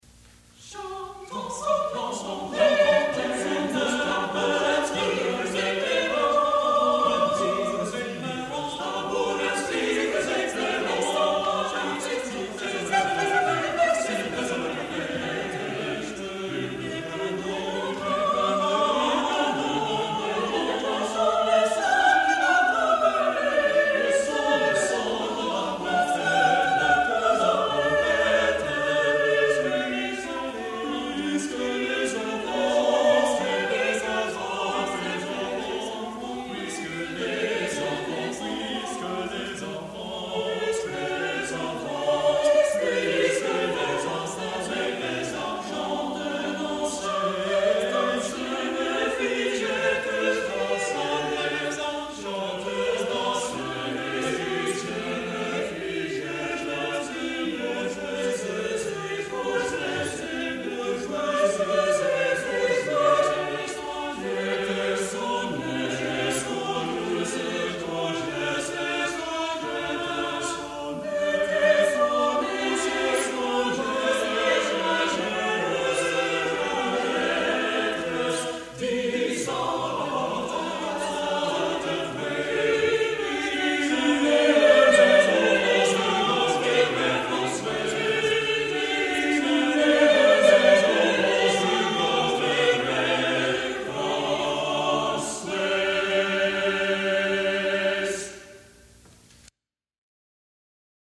| Vocal Ensemble 'Mirror to Apollo' 1978